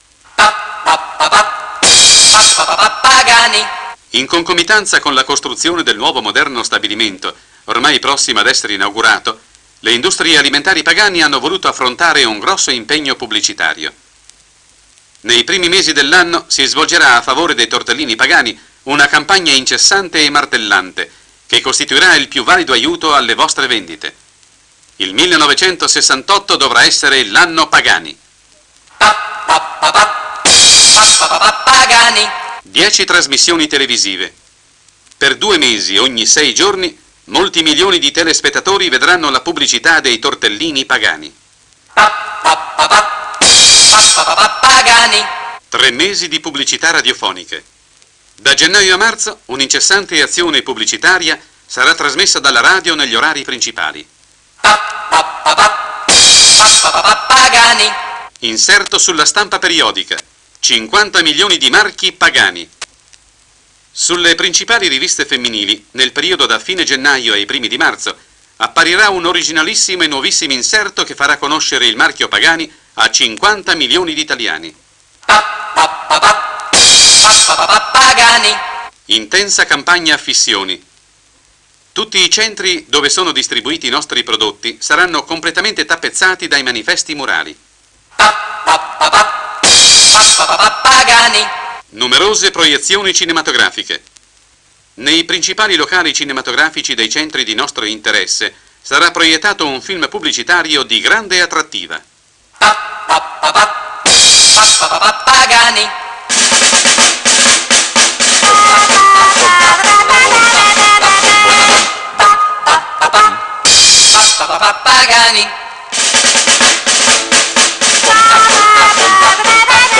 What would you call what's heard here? Announcement: 1968 advertising campaign description